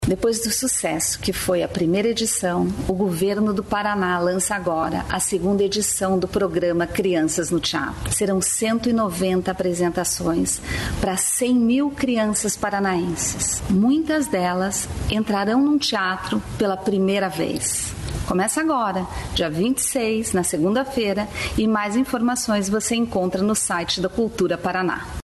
Sonora da secretária da Cultura, Luciana Casagrande, sobre a nova edição do Crianças no Teatro